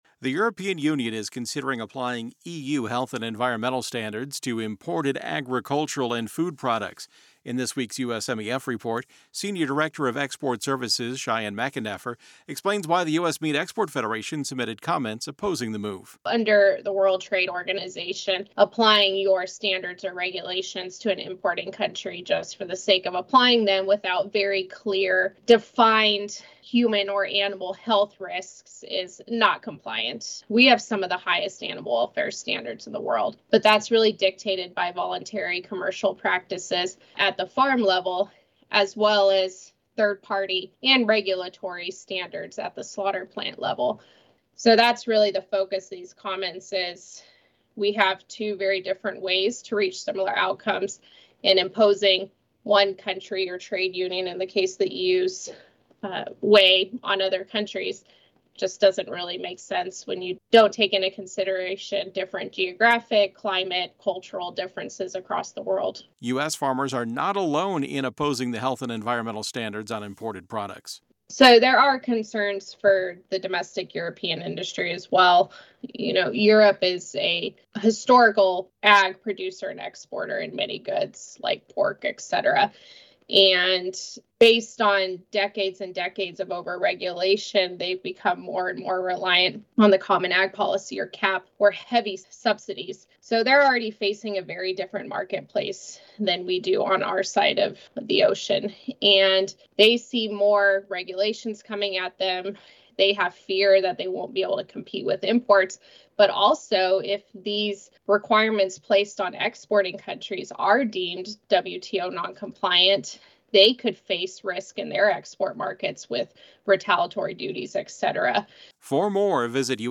In this audio report